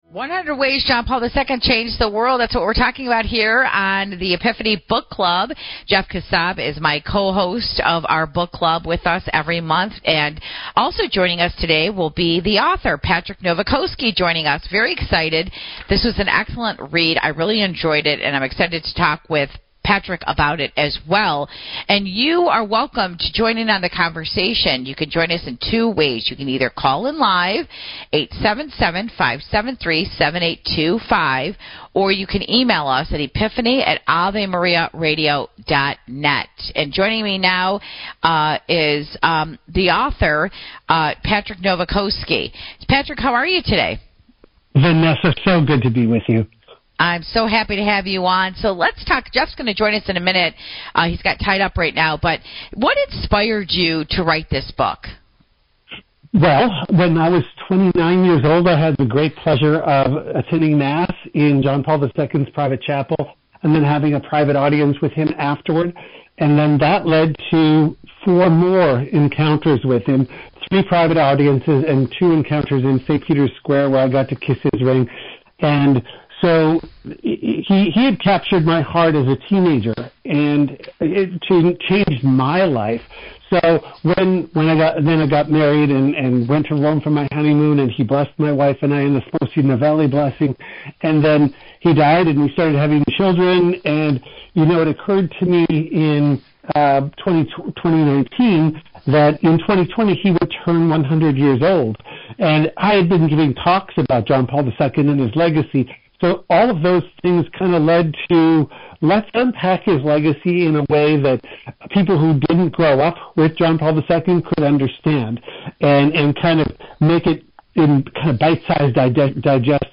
NOTE: Audio problems persisted through the first half of the interview but were corrected in the second segment.